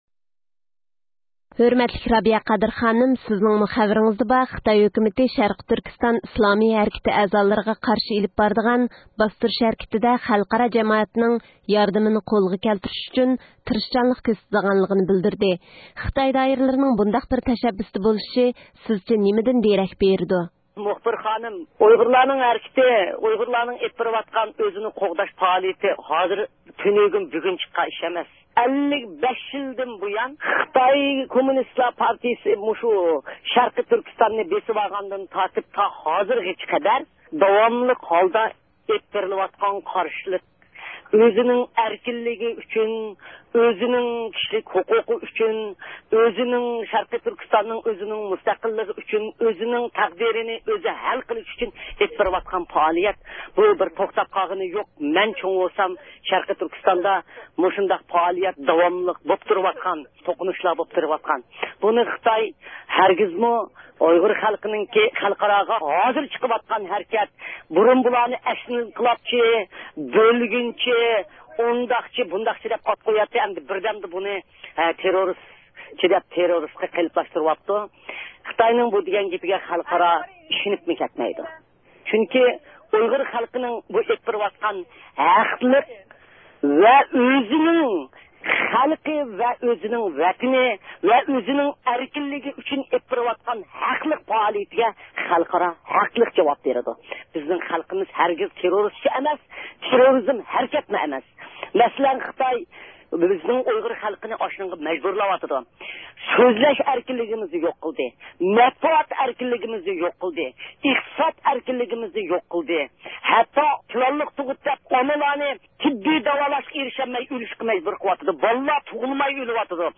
بىراق خىتاي دائىرىلىرىنىڭ بۇ مەقسىدىنىڭ تېررورچىللىقا زەربە بېرىش كۈرىشىدىن پايدىلىنىپ، يەنە بىر قېتىم ئۇيغۇرلارنى باستۇرۇش ھەركىتى ئېلىپ بېرىش ئۈچۈن يول ئېچىش ئىكەنلىكىنى بىلدۈرگەن ئۇيغۇر مىللىي ھەرىكىتىنىڭ سىياسىي رەھبىرى رابىيە قادىر خانىم، ئىستانسىمىزنىڭ زىيارىتىنى قوبۇل قىلىپ ئۆز قاراشلىرىنى بايان قىلدى.